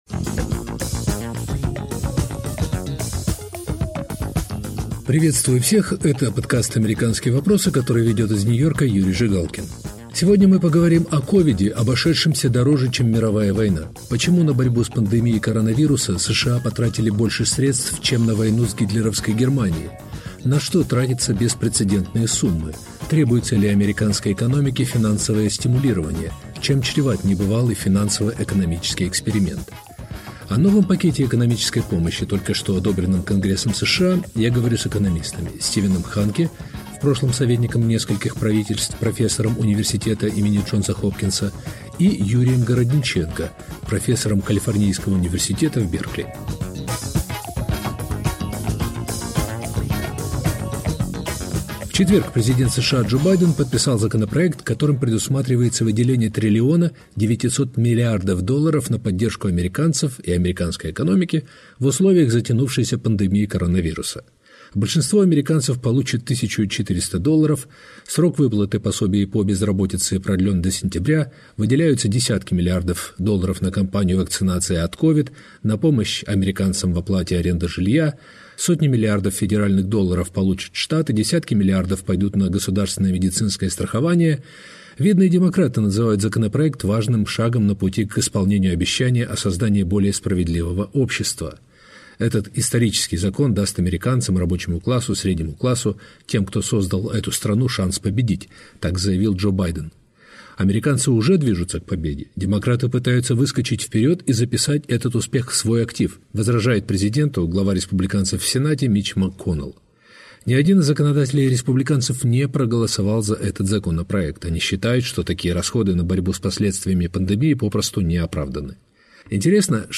Почему на борьбу с пандемией коронаруса США потратили больше средств, чем на войну с гитлеровской Германией? На что тратятся беспрецедентные суммы? О новом пакете экономической помощи, только что одобренном Конгрессом США, я говорю с экономистами